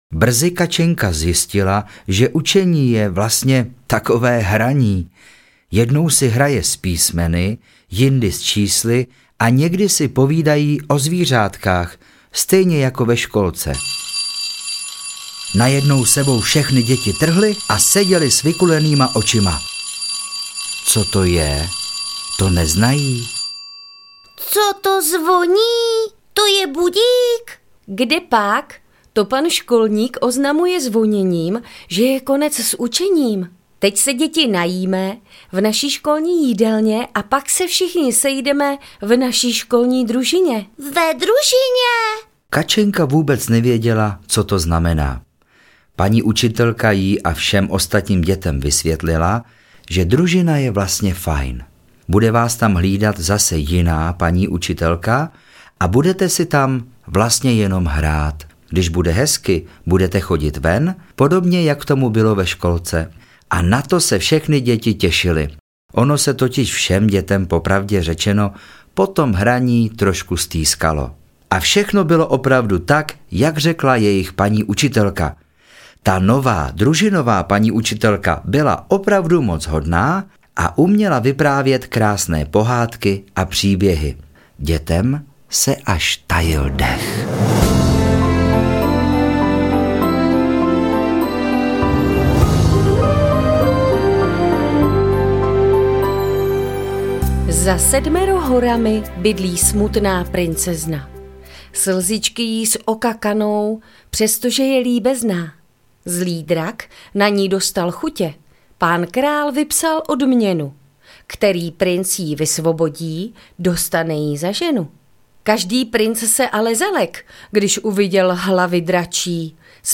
Kačenka jde do školy 2 audiokniha
Ukázka z knihy
Vyprávění, básničky, říkanky a písničky vytvářejí celiství obraz dětského pohledu na svět kolem sebe a mají rozvinout fantazii. Kačenka zažije svůj první den ve škole, seznámí se s učením, navštíví ZOO, napíše svůj první dopis Ježíškovi, pojede na školu v přírodě a dostane závěrečné vysvědčení.